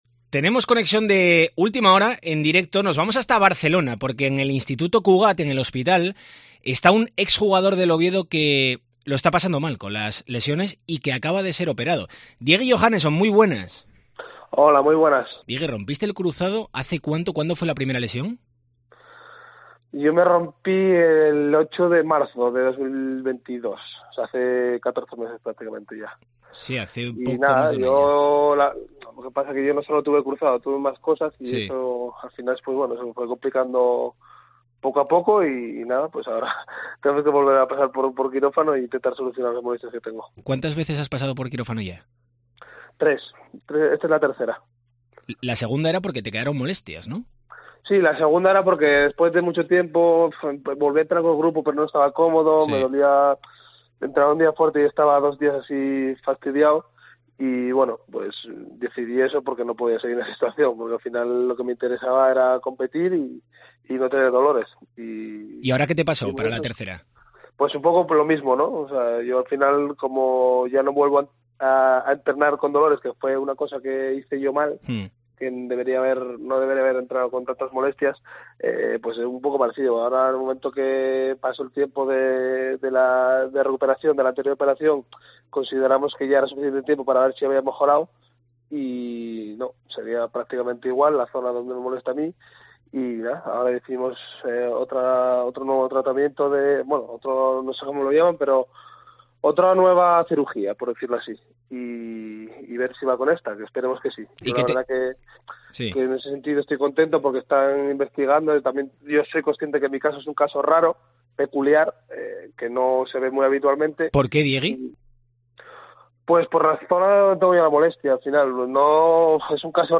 ENTREVISTA DCA
Desde la camilla del hospital, pocas horas después de su tercera operación de rodilla en apenas 14 meses, el ex del Oviedo atiende la llamada de COPE Asturias.